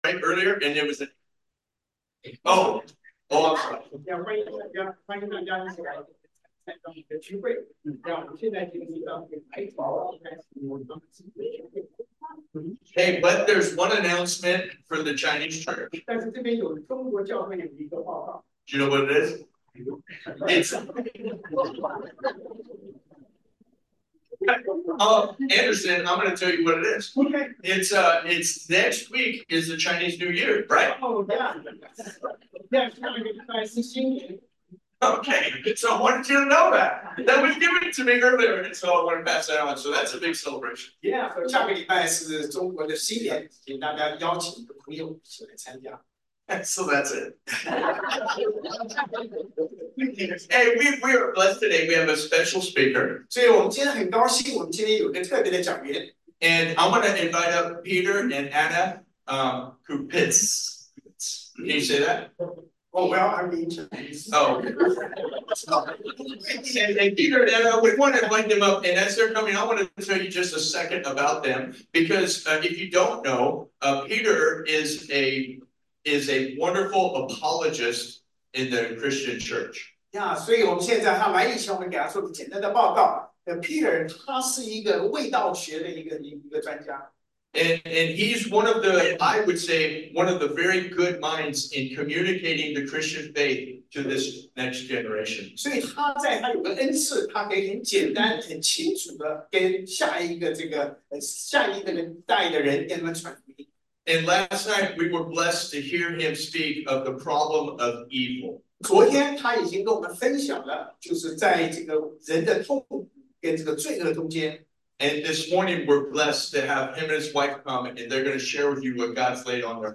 2 Corinthians 10:5 Service Type: Sunday AM Synopsis